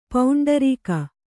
♪ pauṇḍarīka